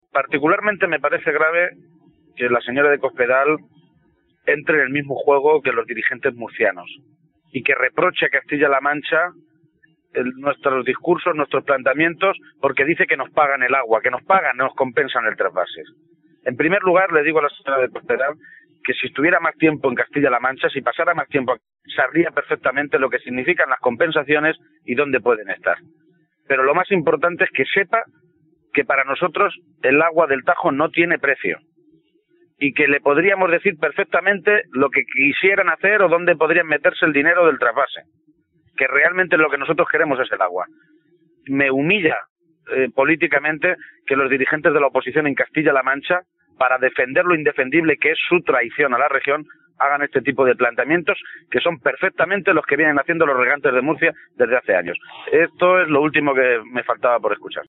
Así lo aseguró García-Page a preguntas de los medios con motivo de un acto de ratificación del Hermanamiento entre la Hermandad de Donantes de Sangre de Toledo y la Hermandad de Donantes de Sangre de Esposende (Portugal) celebrado hoy en Toledo.
Cortes de audio de la rueda de prensa